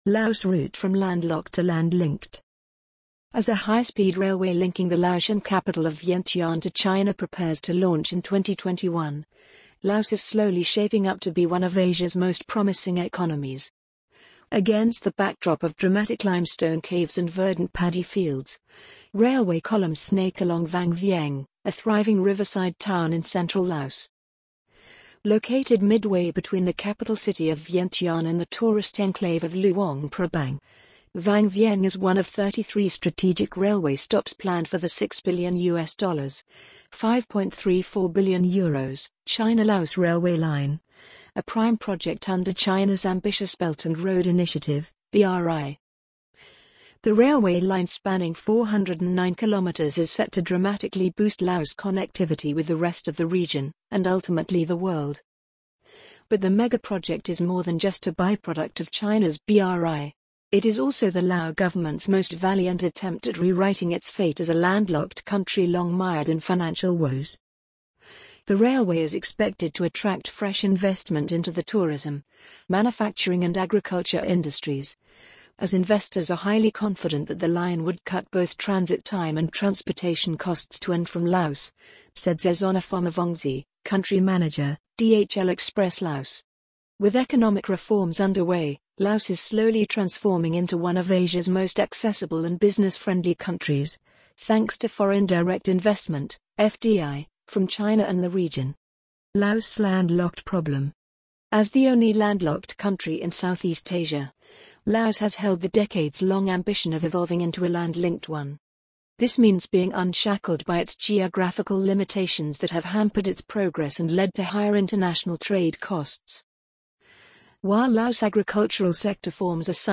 amazon_polly_3428.mp3